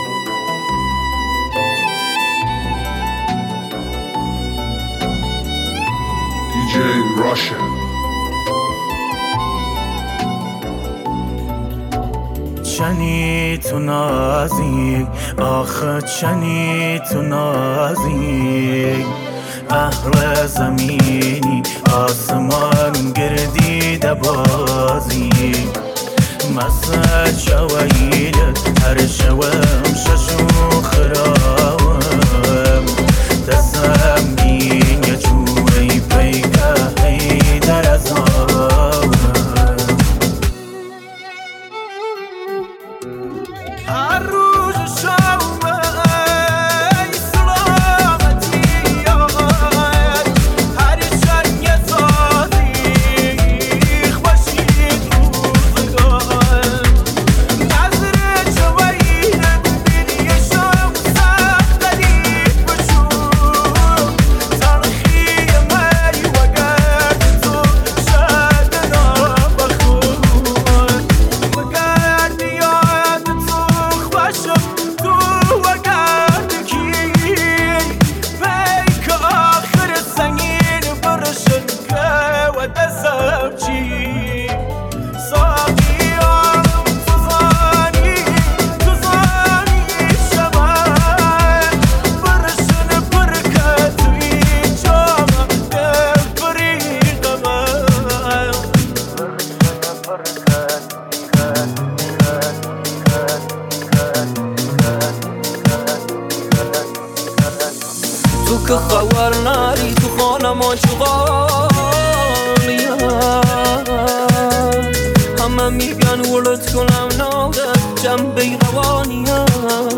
آهنگ ایرانی